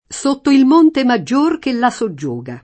soggiogare v.; soggiogo [ S o JJ1g o ], ‑ghi